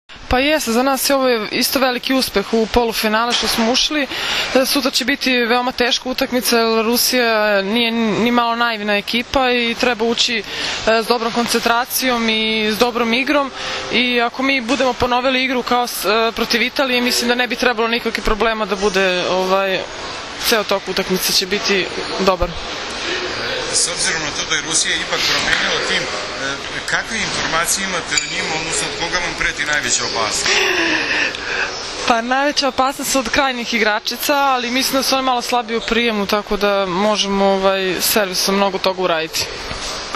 IZJAVA BRIŽITKE MOLNAR